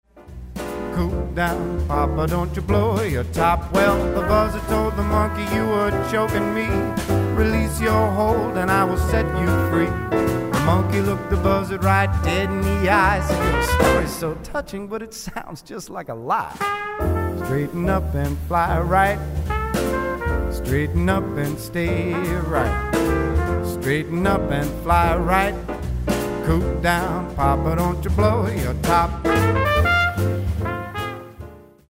Vocalist/Producer/Bandleader (Massachusetts)
At the heart of him is Jazz.